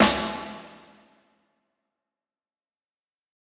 (SNARE) - Woke up in the Hills.wav